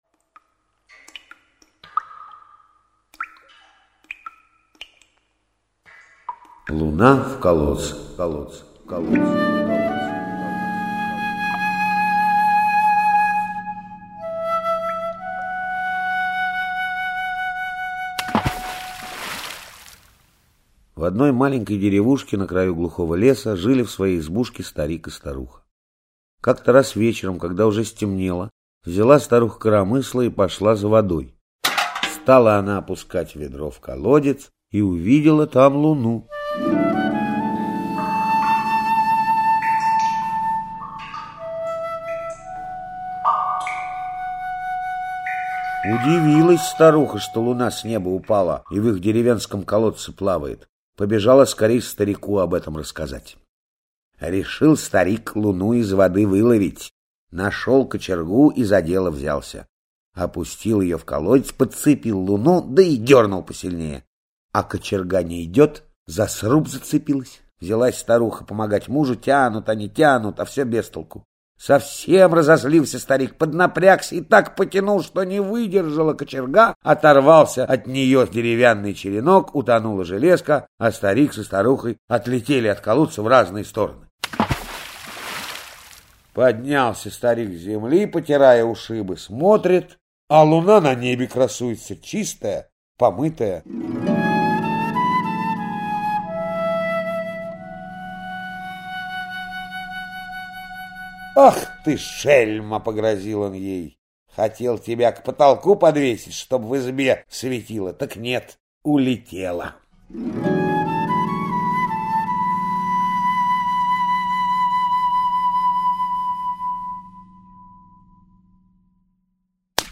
Луна в колодце - чувашская аудиосказка - слушать оналайн